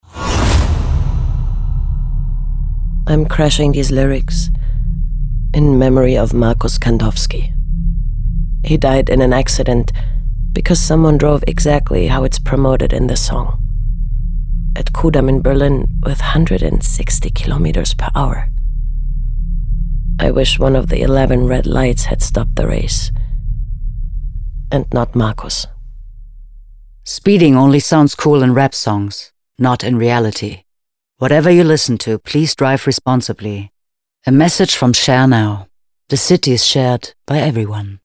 L’argent ex æquo  pour 3 spots de la campagne allemande « Crashed Lyrics », une sensibilisation audacieuse et originale à la conduite responsable financée par la société de co-voiturage Share Now. Sur une radio qui a accepté le challenge, les spots étaient diffusés en interruption de chansons dans lesquels la conduite rapide, les comportements à risques, figurent dans les paroles.